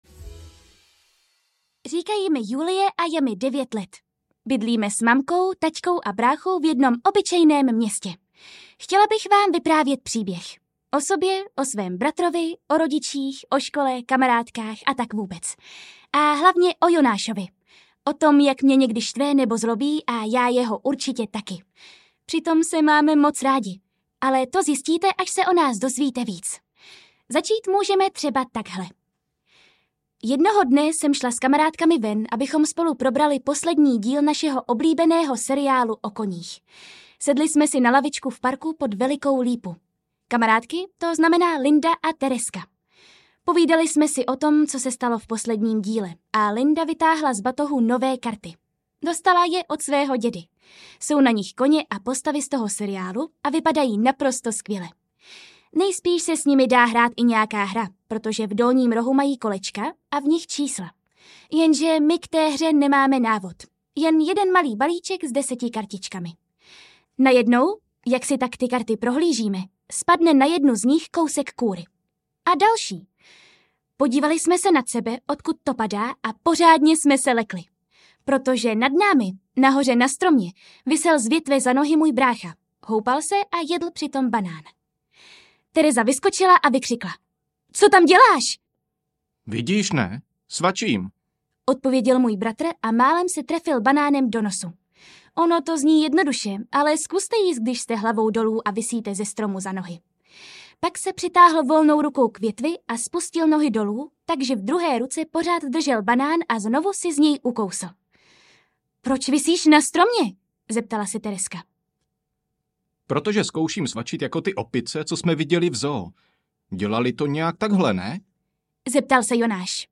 Můj brácha Tornádo audiokniha
Ukázka z knihy